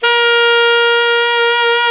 SAX ALTOMP0E.wav